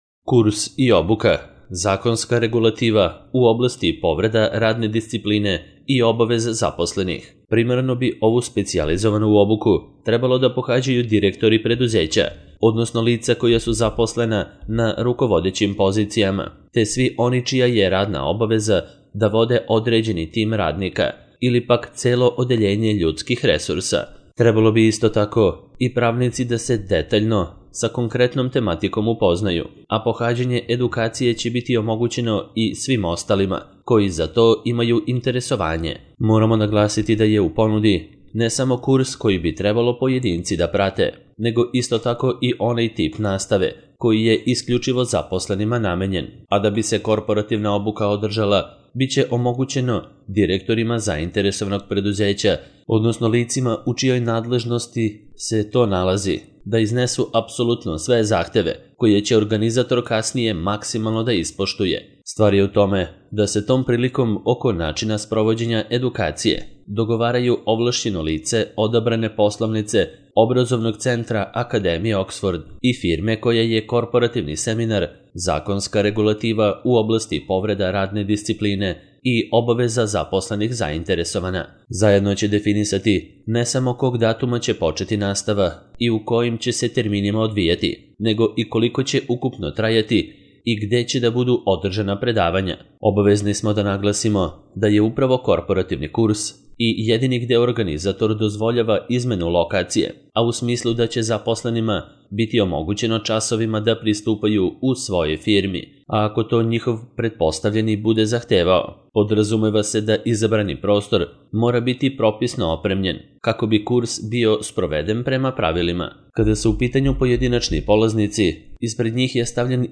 Kurs i obuka - zakonska regulativa u oblasti povreda radne discipline i obaveza zaposlenih - Audio verzija